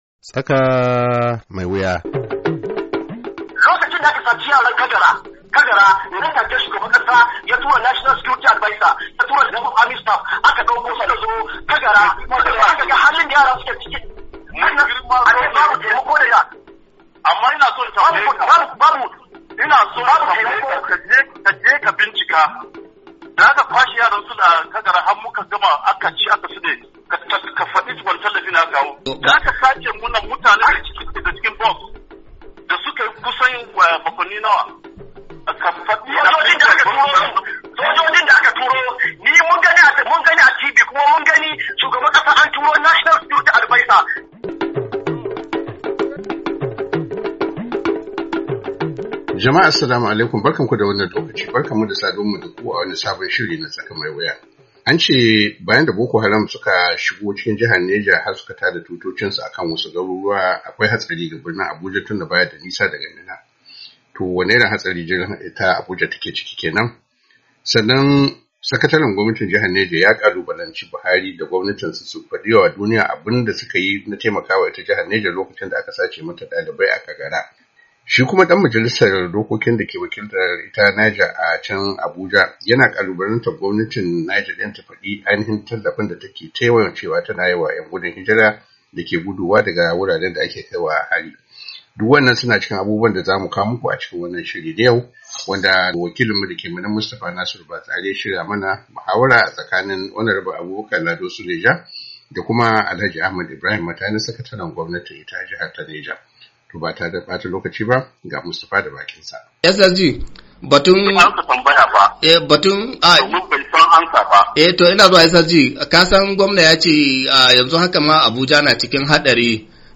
TSAKA MAI WUYA: Muhawara Kan Matsalar Tsaro a Jihar Neja, Kashi Na Hudu - Yuni 08, 2021